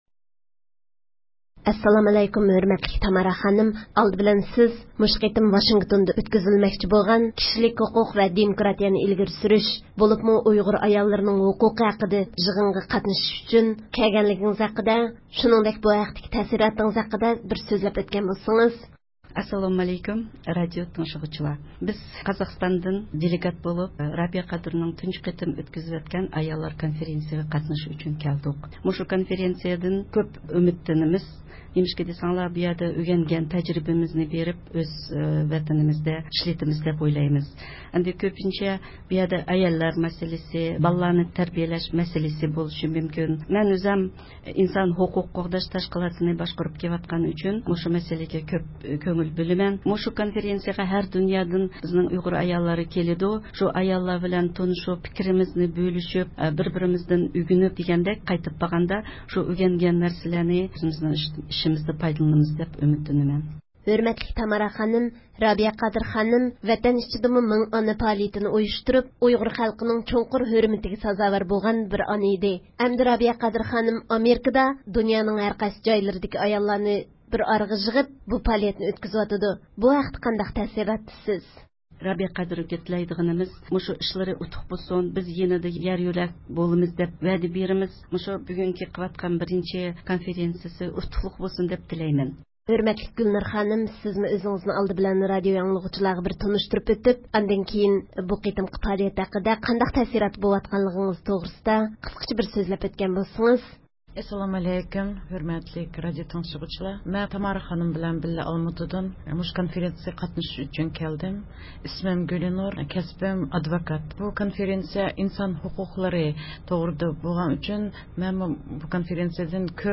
دېموكراتىيە تەربىيىسى يىغىنىغا قاتنىشىش ئۈچۈن كەلگەن ئۇيغۇر ئايال ۋەكىللىرى رادىئومىزدا زىيارەتتە بولدى